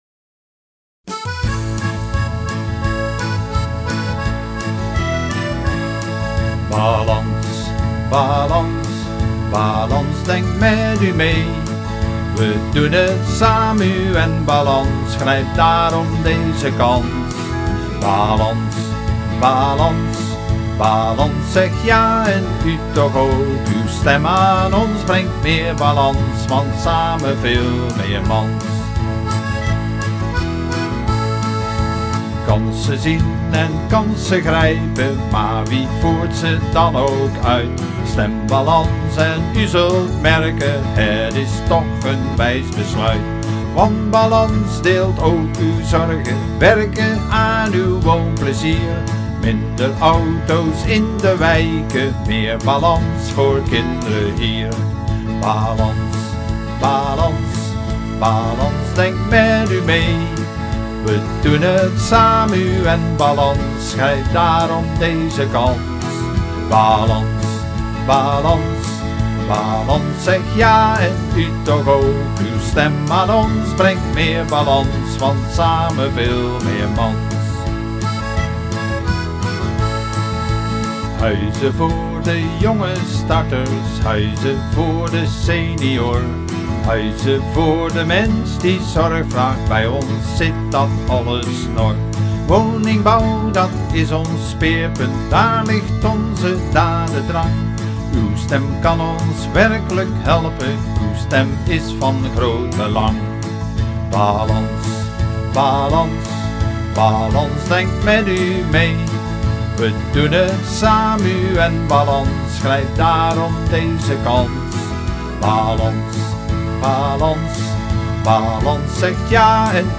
BALANS-campagnelied.mp3